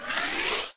mushroomWakeup.ogg